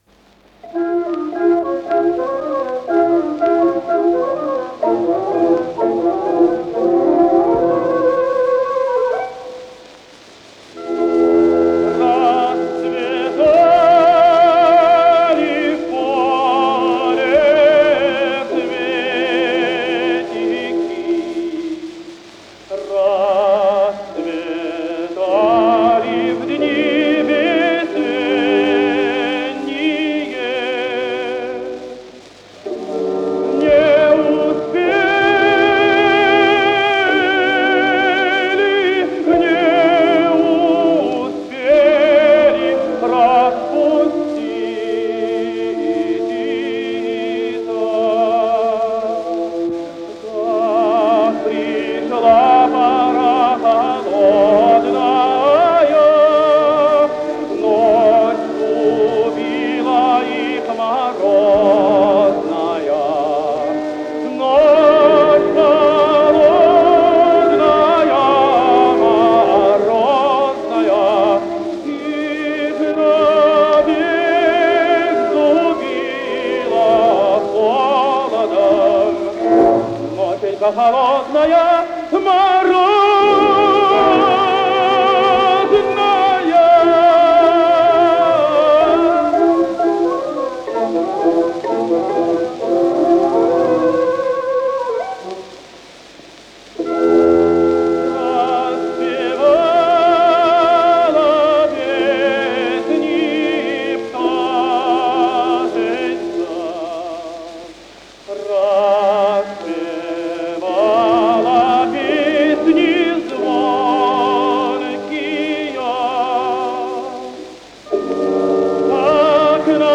с профессиональной магнитной ленты
ИсполнителиЛеонид Собинов - пение
АккомпаниментОркестр